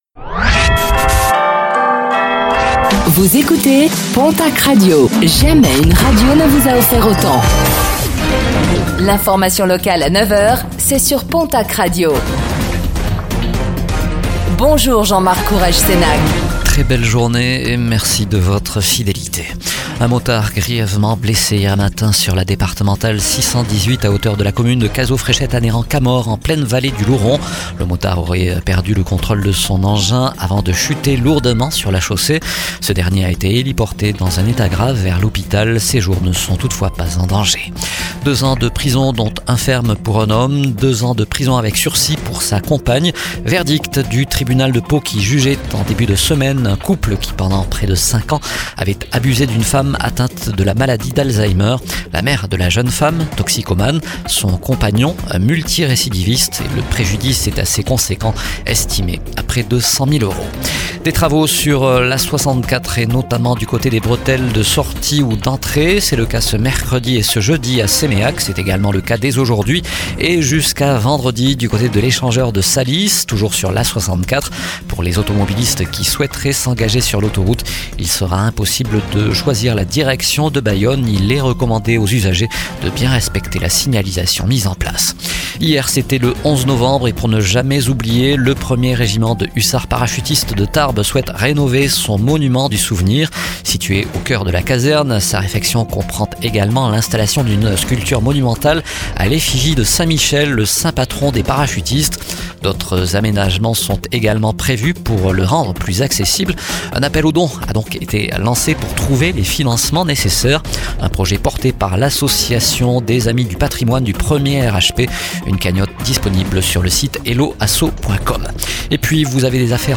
Réécoutez le flash d'information locale de ce mercredi 12 novembre 2025